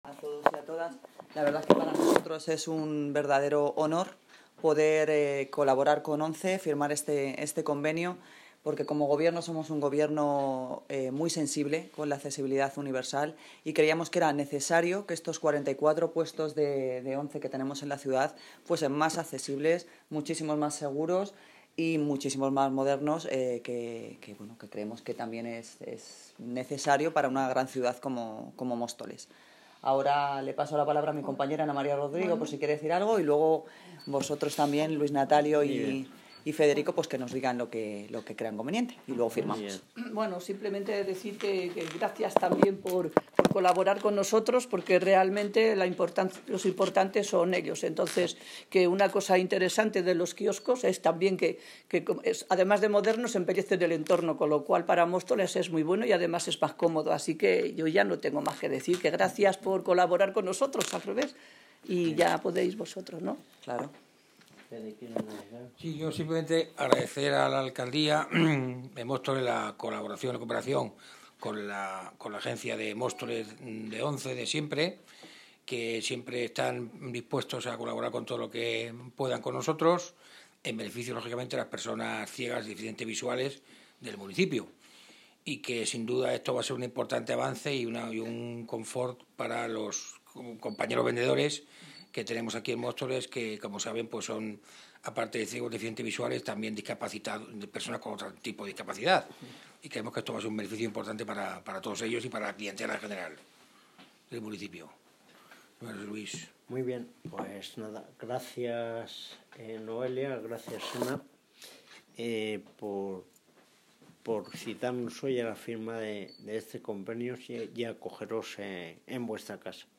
Audio - Noelia Posse (Alcaldesa de Móstoles) Sobre Firma convenio ONCE
Audio - Noelia Posse (Alcaldesa de Móstoles) Sobre Firma convenio ONCE.mp3